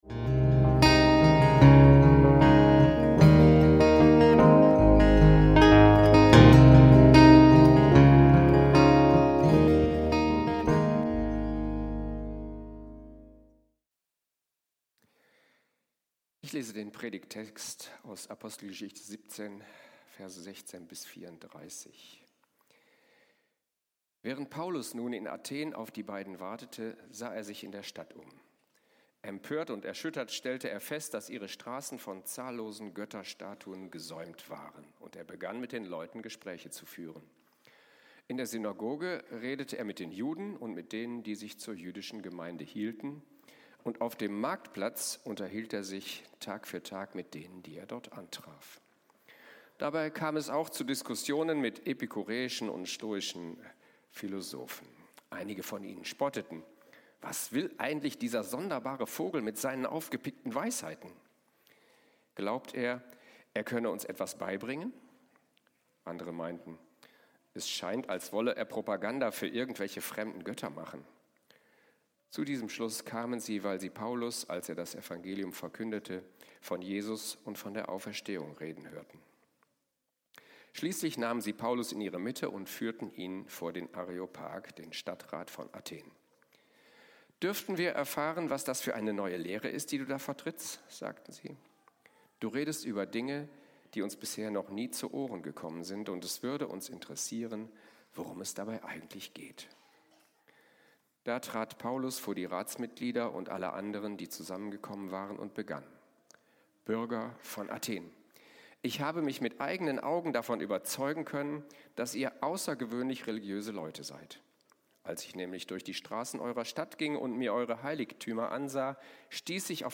Verkündigen und verstehen - Gemeinde mit Mission - Predigt vom 01.03.2026